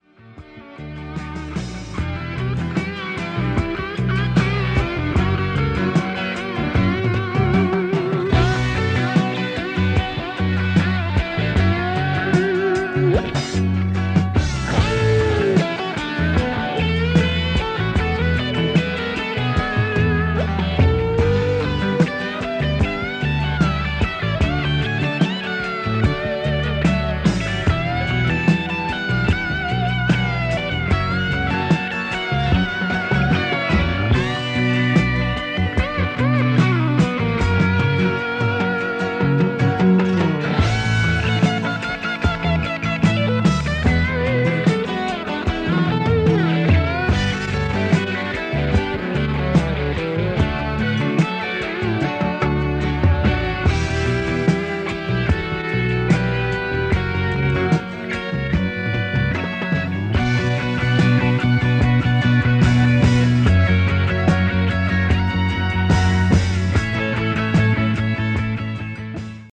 Below is a test recording made with the SD1030 and played back by it:
Track System: 4-track, 2-channel stereo
Noise Reduction: B
Marantz-SD1030-Test-recording.mp3